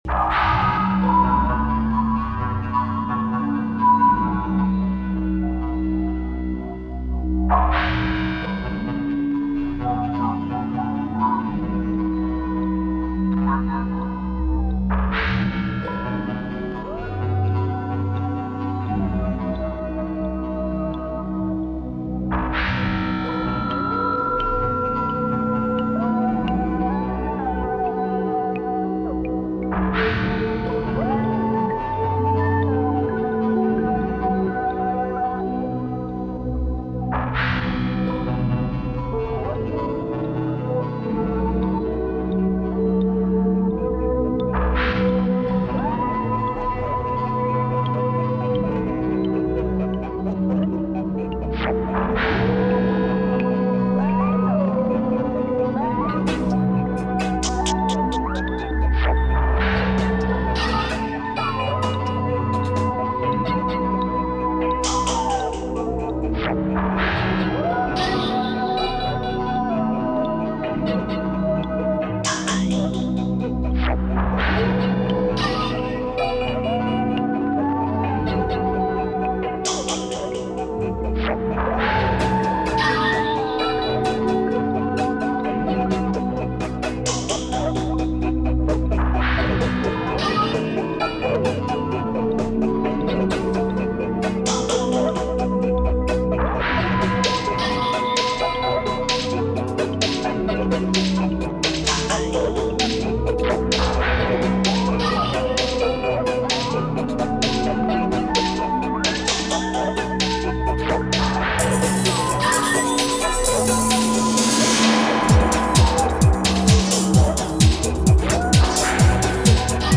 Здоровая электронная классика.
Categories: Ambient, Dub, House.